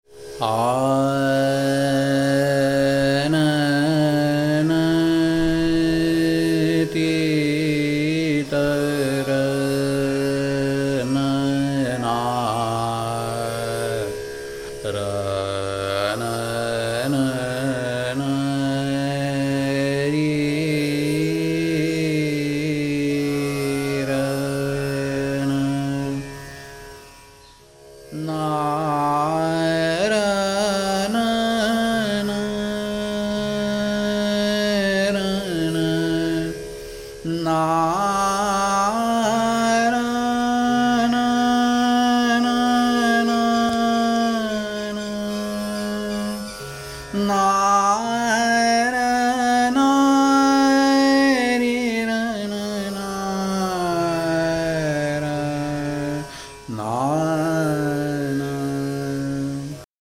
vocalist
His brief Dhrupad-infused recording – transcribed in full below – explores its strange geometric layout to superb effect, interspersing Todi’s rgrS pakad with winding uttarang ornaments such as MD(PD); D(PDn); nS(nS). 4 of the 7 swaras (r-P-D-n) are ‘imperfect’ (i.e. with no swara a perfect 5th above them), and the rare ‘tivra Ma + komal nisangati leaves its stamp on the mood.
[alap, e.g. 0:01] (D)S, r(gr) g, g(rgr) g(r) r(S)S, S, nD, P(M) D S(nSn) S, r(gr) g(r) g(rS) S; g(rgr)g P(MPM) D(PDP) P(M) M(g)g, gM(PM) D(PD)n (D)n, n(D) D(P)P, M D(PD) n(Sn)S n n(D) D(P) P(Mgr)r, S(grg) r(S) S…
• Tanpura: Sa–Pa